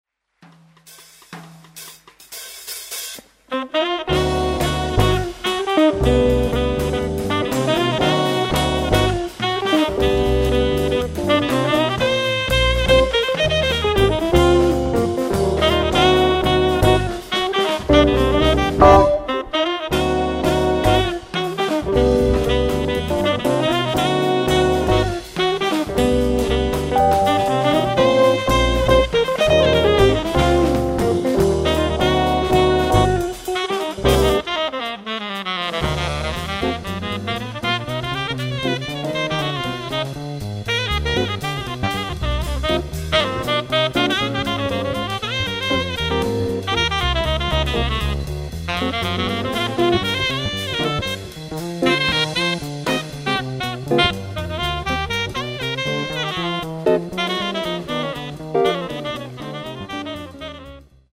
Catalogue -> Jazz & almost -> Collections, Jams, Live
keyboards, Fender Rhodes piano
alto & tenor saxes
guitar
acoustic bass
drums